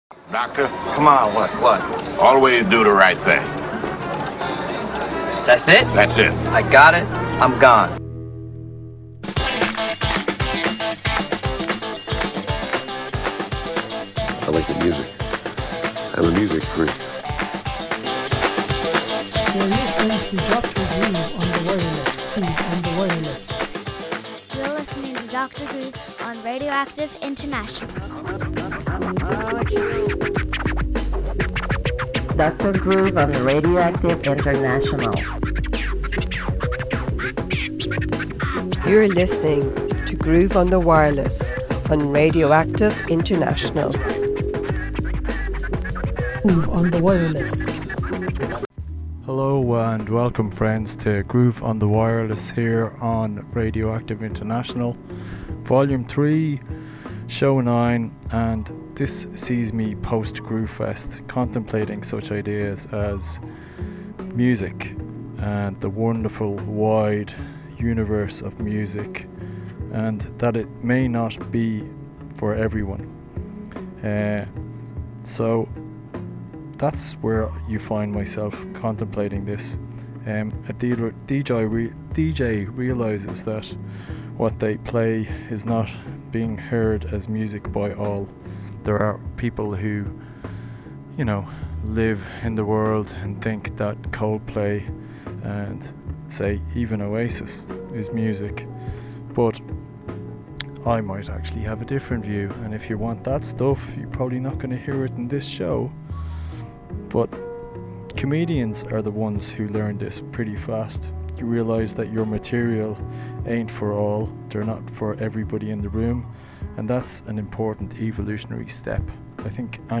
by playing a very quite record.
afro future funk